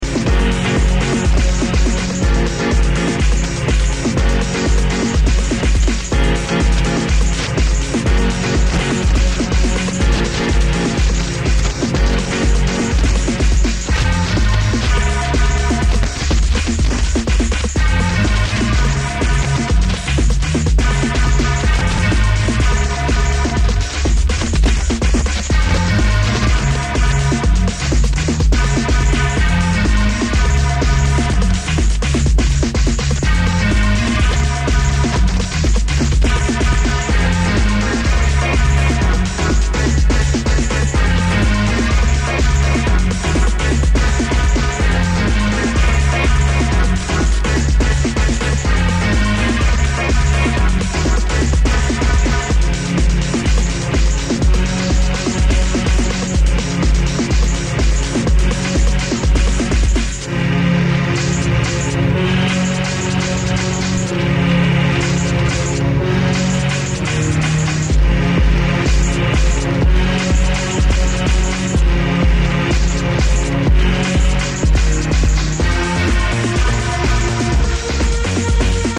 avec son baladeur dans un train